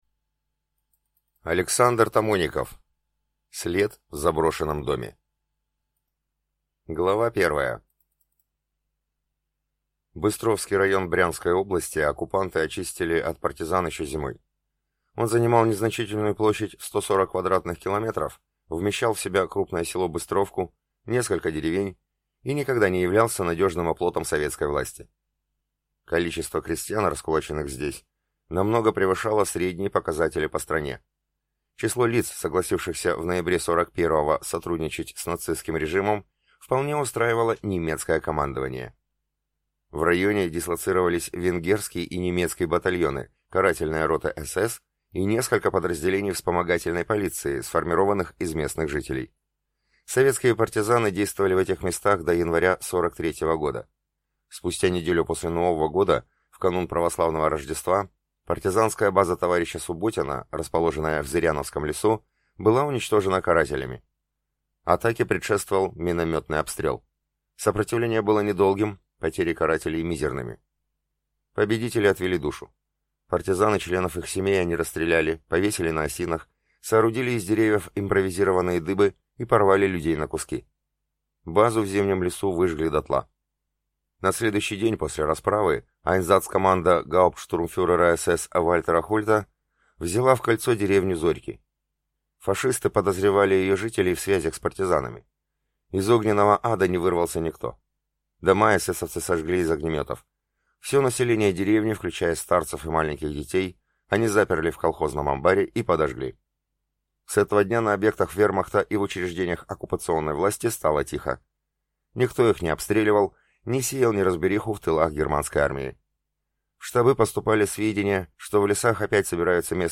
Прослушать фрагмент аудиокниги След в заброшенном доме Александр Тамоников Произведений: 34 Скачать бесплатно книгу Скачать в MP3 Вы скачиваете фрагмент книги, предоставленный издательством